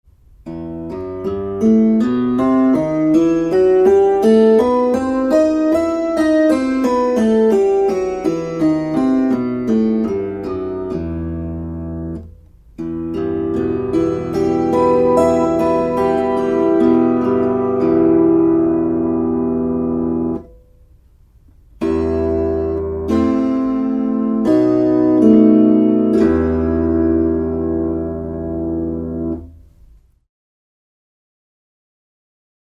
Kuuntele e-molli. fis dis Opettele duurit C G D A E F B Es As mollit a h fis cis d g c f Tästä pääset harjoittelun etusivulle .